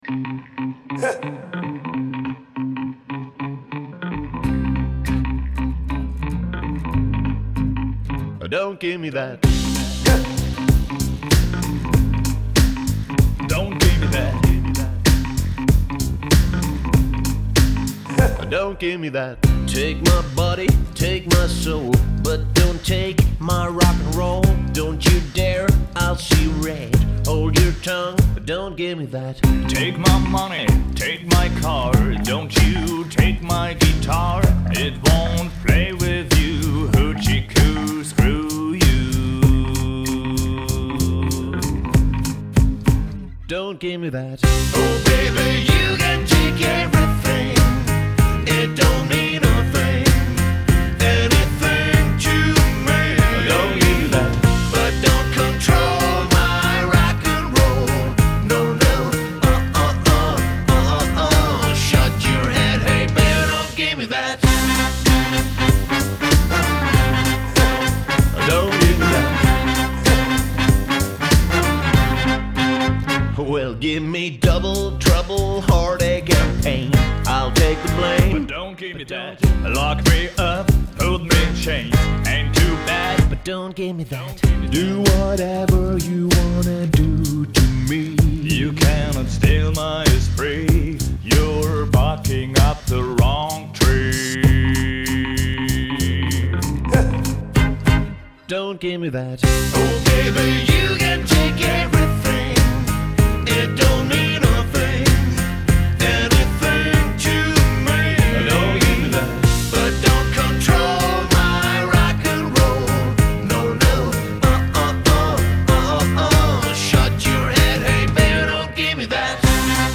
Rock 2010er